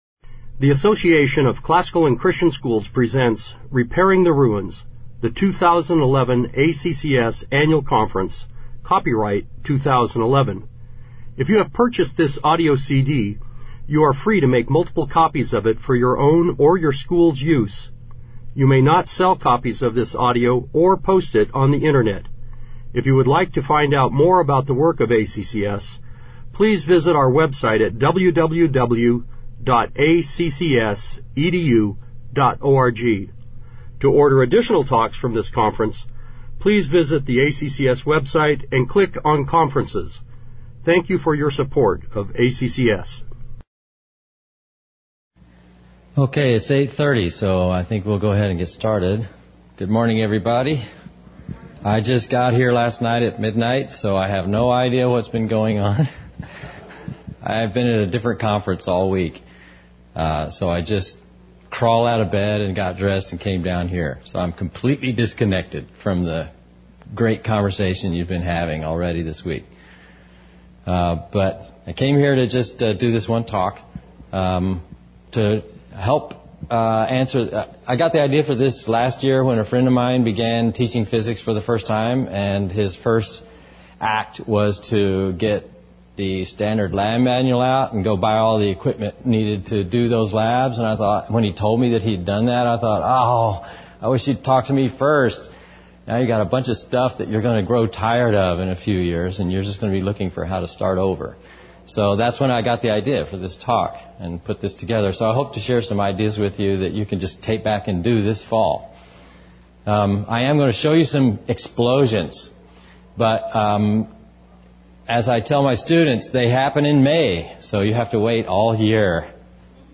2011 Workshop Talk | 1:01:45 | 7-12, Science